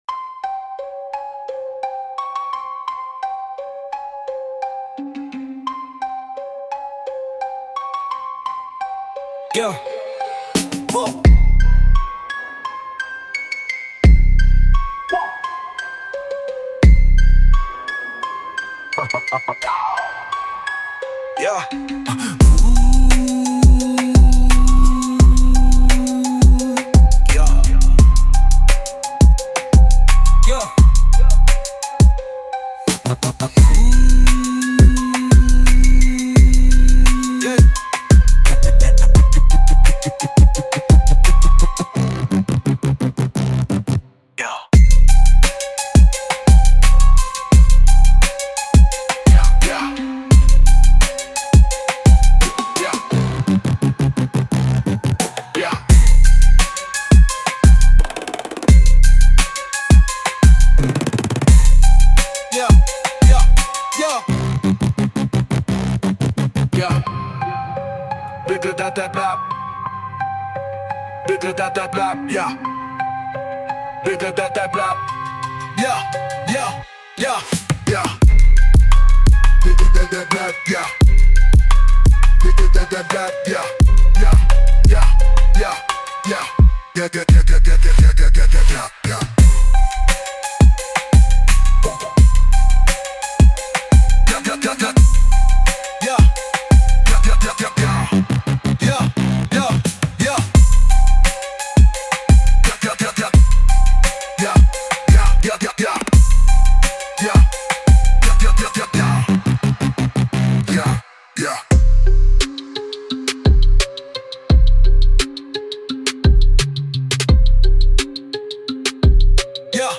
Instrumental - RUN from Those who Censor - Real Liberty Media dot XYZ- 4.00 mins